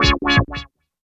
84 WAH-WAH-R.wav